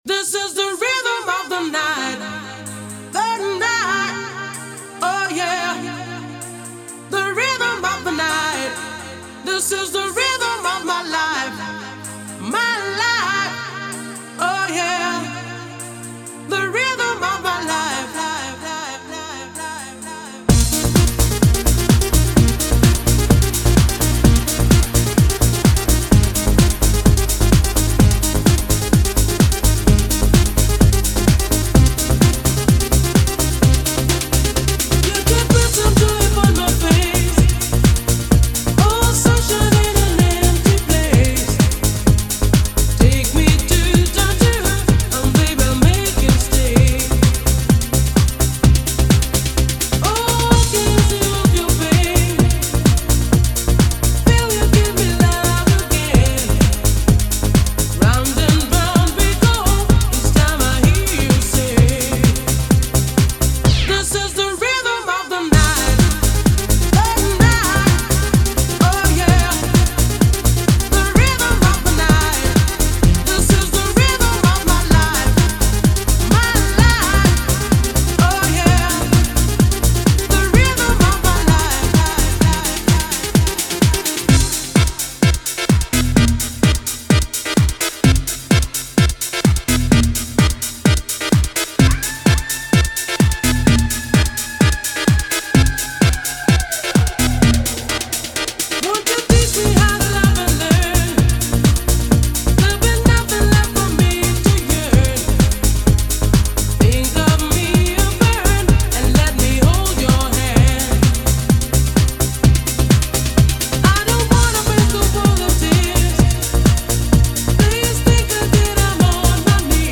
The Timeless Sound of the Dancefloor Reimagined